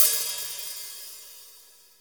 CM HAT OP 23.wav